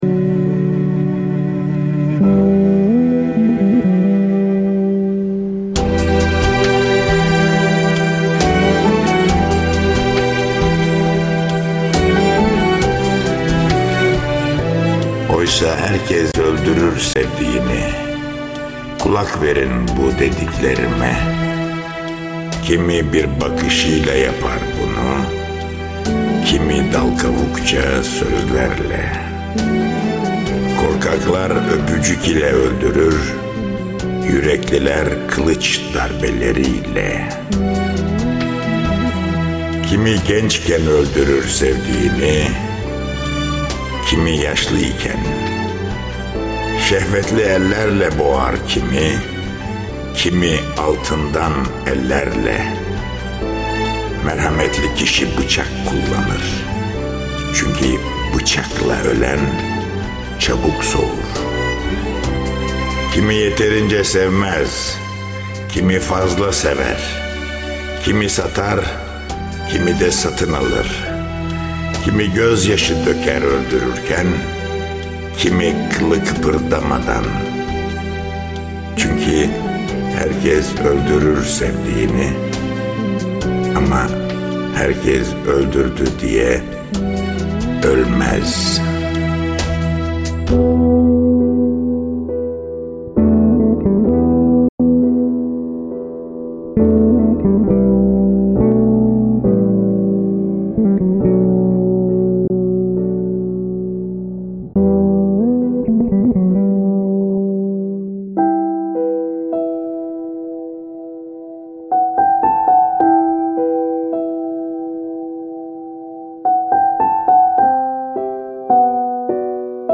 SESLEND�RME:TUNCEL KURT�Z(ezel dizsinin ramiz day�s�)